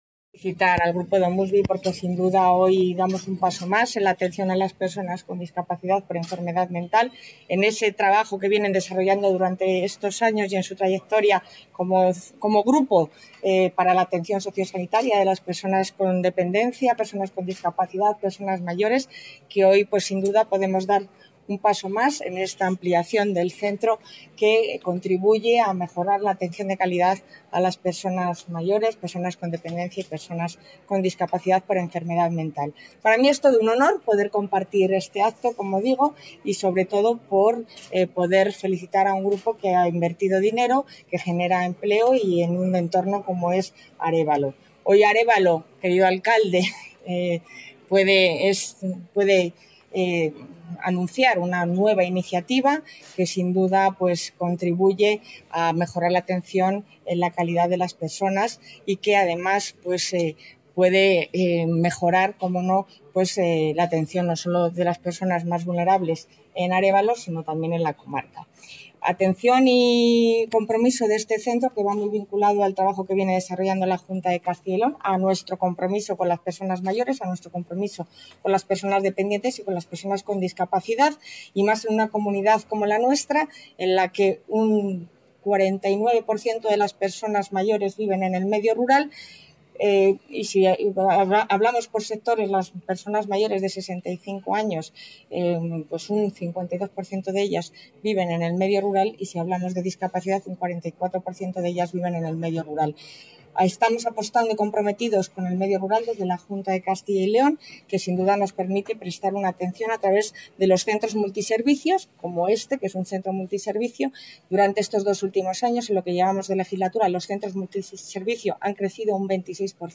Declaraciones de la consejera de Familia e Igualdad de Oportunidades.